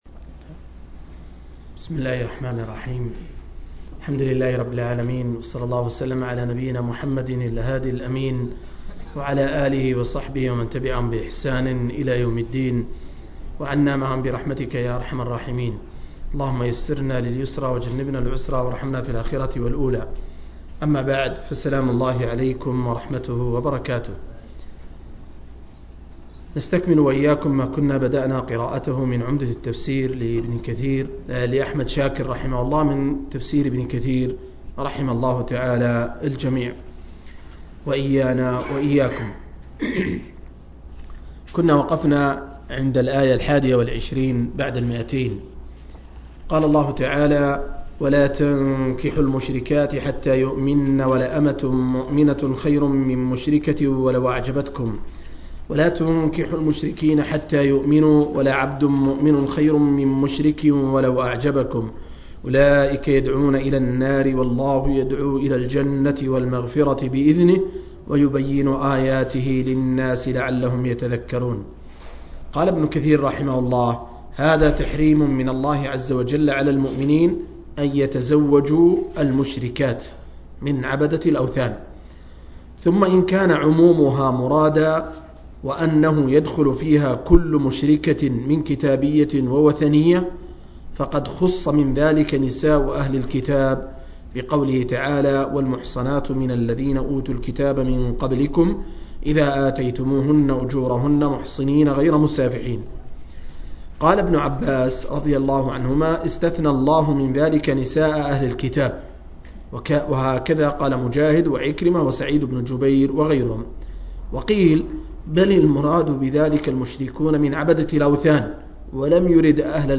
044- عمدة التفسير عن الحافظ ابن كثير – قراءة وتعليق – تفسير سورة البقرة (الآيات 223-220)